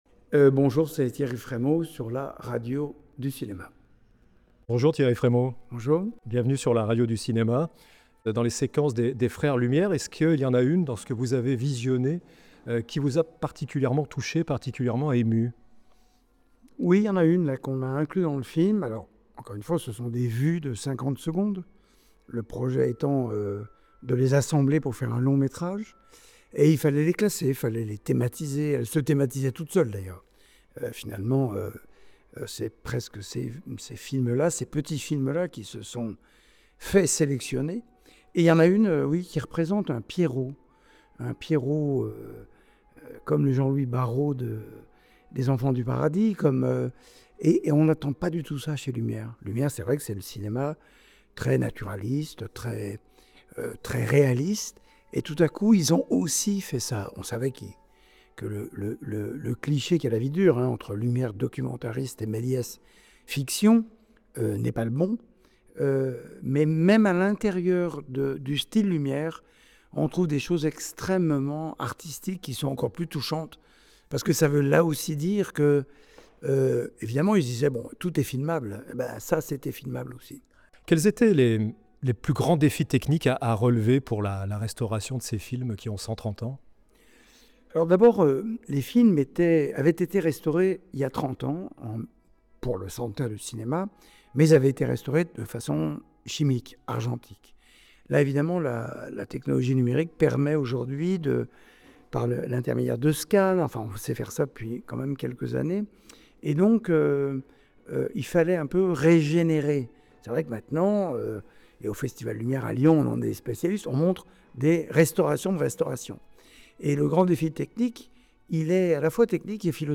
Dans notre échange, Thierry Frémaux rappelle l’importance de la rue du Premier-Film à Lyon, là où tout a commencé.